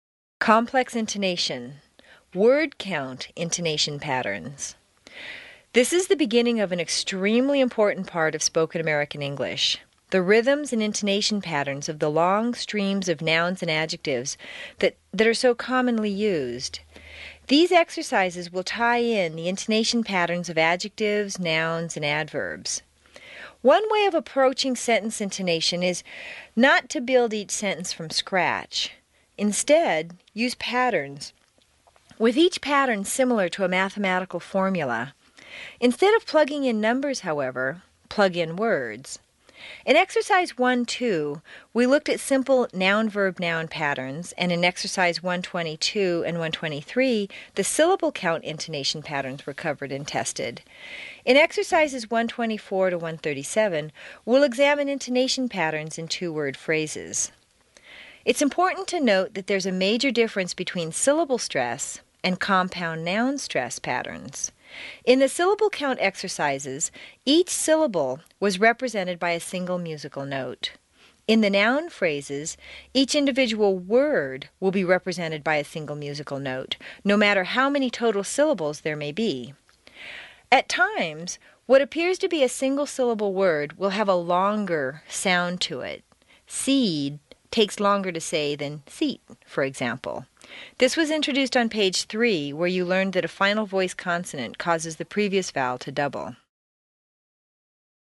Complex Intonation
This is the beginning of an extremely important part of spoken American English—the rhythms and intonation patterns of the long streams of nouns and adjectives that are so commonly used.